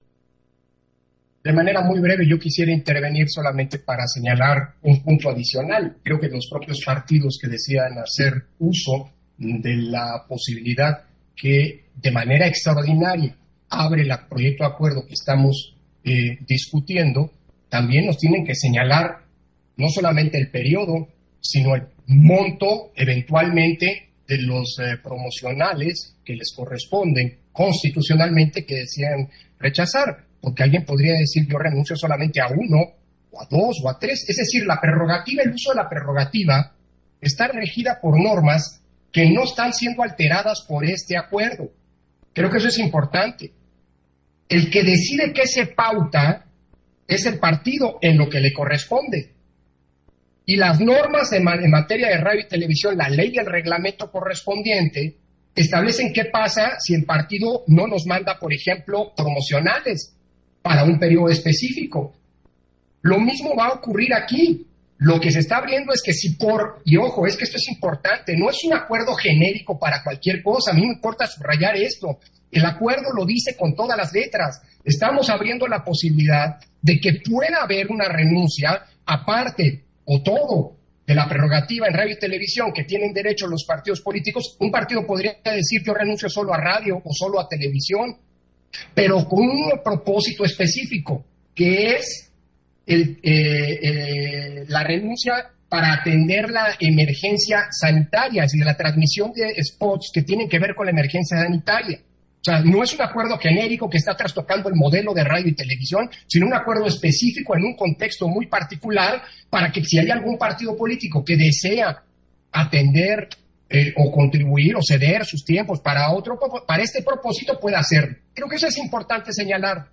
040121_AUDIO_INTERVENCIÓN-CONSEJERO-PDTE.-CÓRDOVA-PUNTO-3-SESIÓN-EXT. - Central Electoral